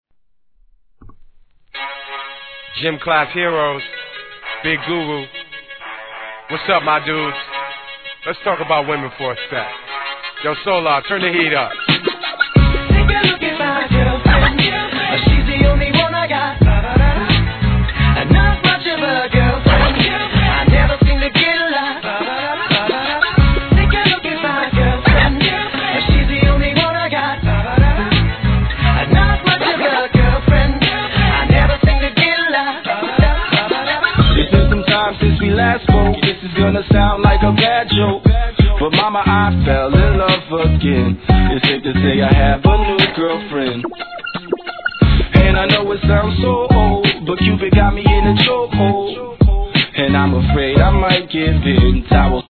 HIP HOP/R&B
一度聴いたら忘れられない「バラララ～♪」のフックのやつです☆